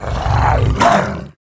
attack_1.wav